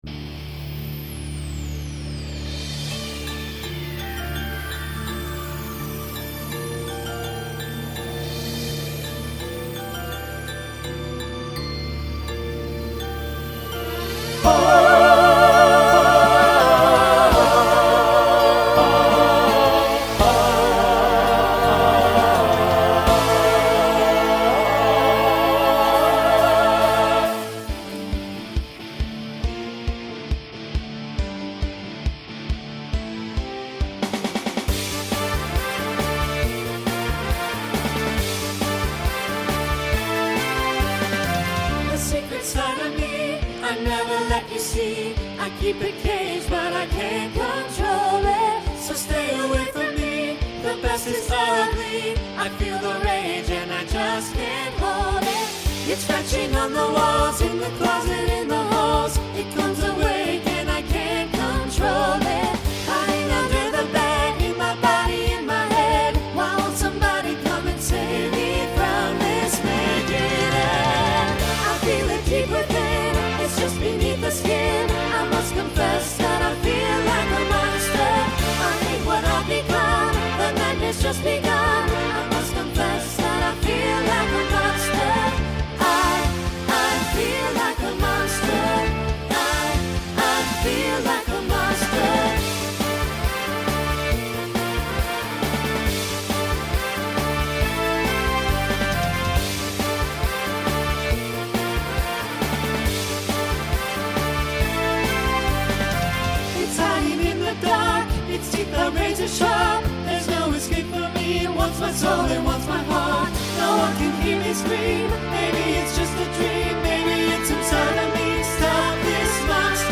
New SSA voicing for 2024.